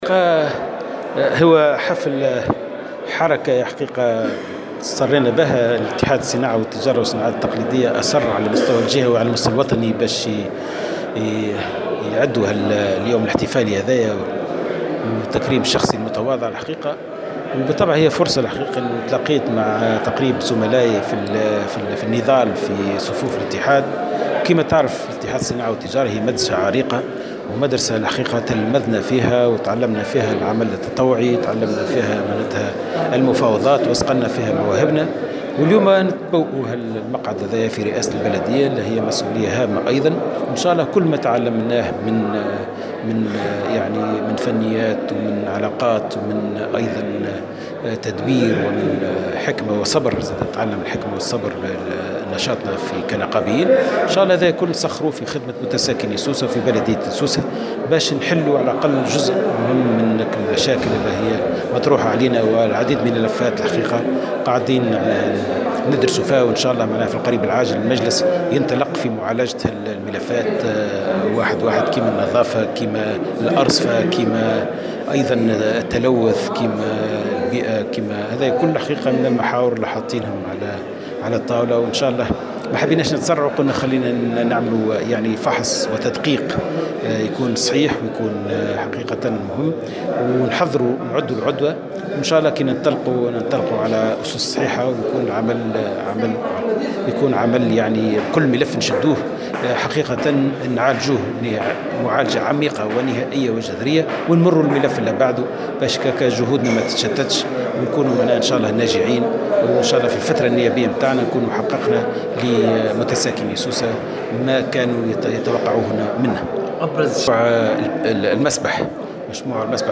وصرّح رئيس بلدية سوسة لموفد "الجوهرة اف ام" على هامش حفل تكريم تم تنظيمه على شرفه من طرف منظمة الأعراف بمقر الاتحاد الجهوي بسوسة وبحضور رئيس المنظمة سمير ماجول، ان ابرز هذه المشاريع تتمثل في المسبح الأولمبي الذي سيتم انجازه قريبا بعد ان تم ضبط برنامج تمويله واعداد الدراسة الخاصة به بالاضافة إلى مشروع توسيع الملعب الرياضي.